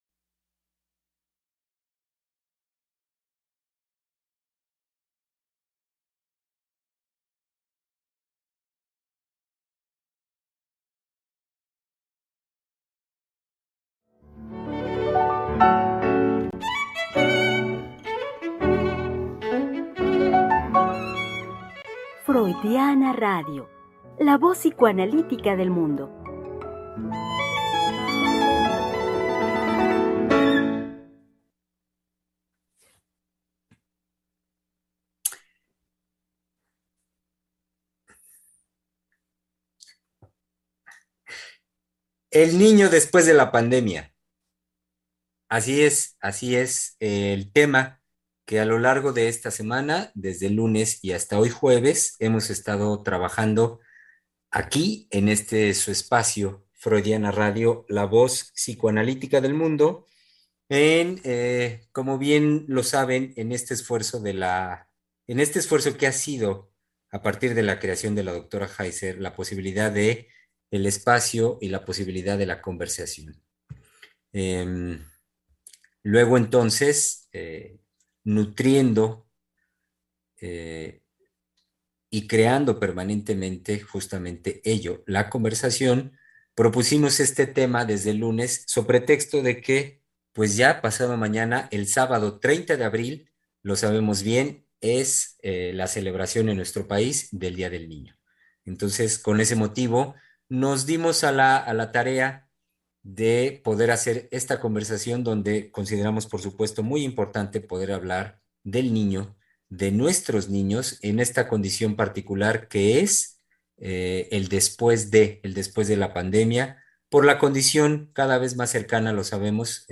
recuerda nuestro programa en vivo se transmitirá en punto de las 19:30 hrs. por Freudiana Radio, no te lo pierdas.